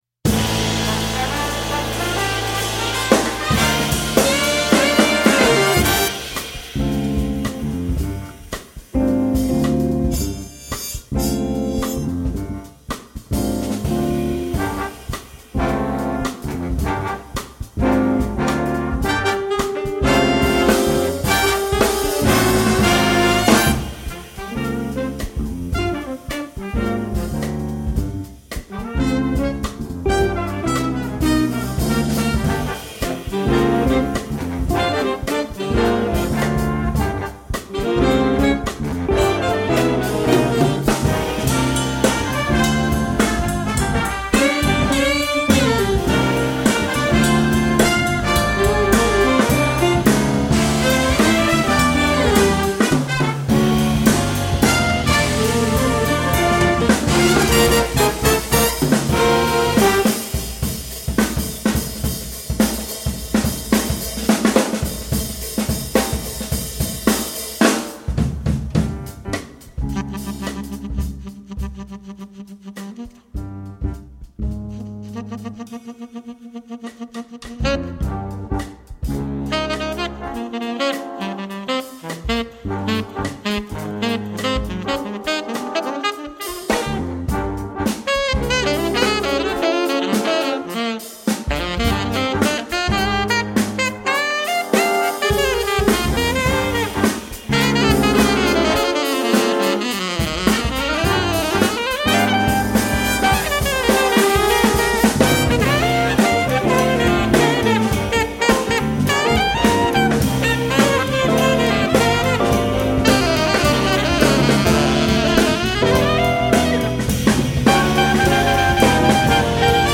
Instrumentation: Big Band (10 brass)